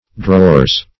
drawers - definition of drawers - synonyms, pronunciation, spelling from Free Dictionary